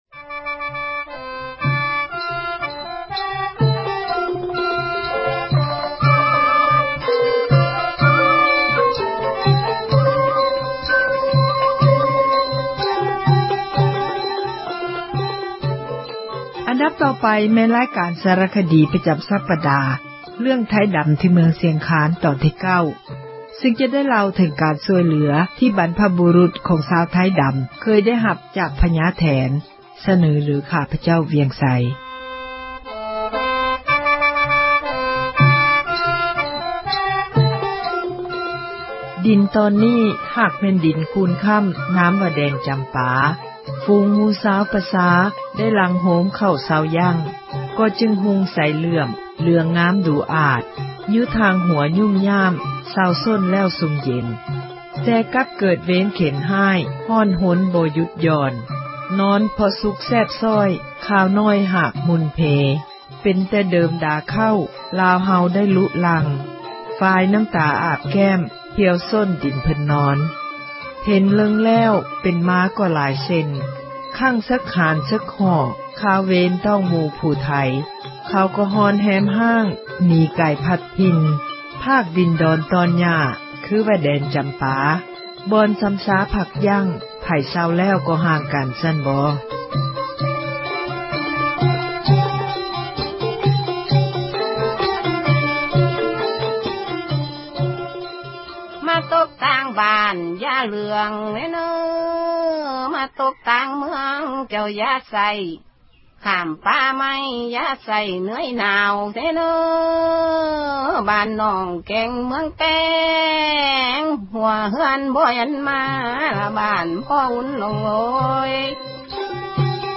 ສາຣະຄະດີ ປະຈຳ ສັປດາ ເຣື້ອງ ”ໄທດຳ ທີ່ເມືອງ ຊຽງຄານ” ຕອນທີ 9 ໃນມື້ນີ້, ຈະໄດ້ເລົ່າເຖິງ ການຊ່ວຍເຫລືອ ທີ່ບັນພະບູຣຸດ ຂອງ ຊາວໄທດຳ ເຄິຍໄດ້ຮັບ ຈາກພຍາແຖນ.